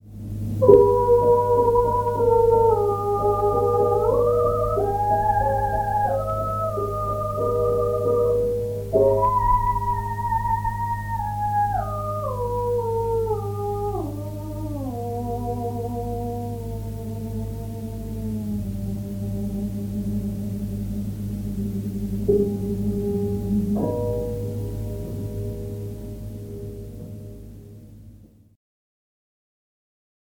Dieci temi – Questo è il terzo dei dieci temi strumentali inseriti nella raccolta dei canti, ed è stato dato dal Maestro il 27 gennaio 1933.